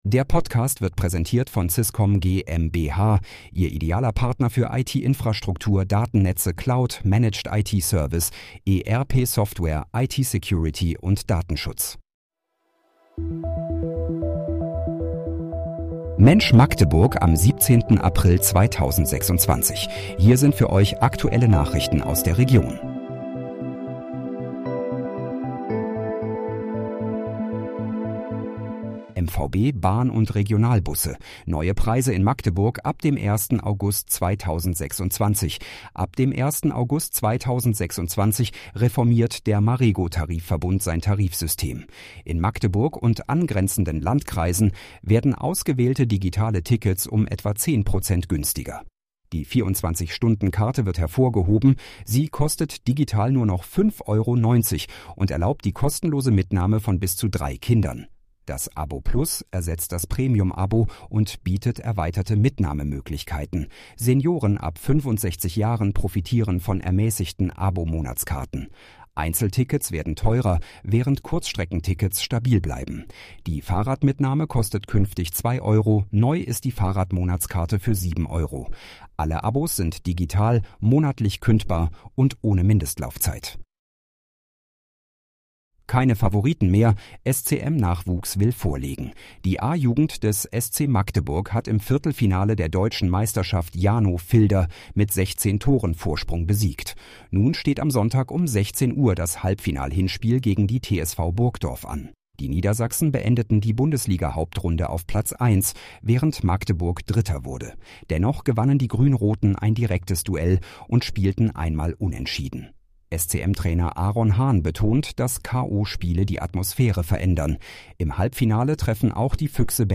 Mensch, Magdeburg: Aktuelle Nachrichten vom 17.04.2026, erstellt mit KI-Unterstützung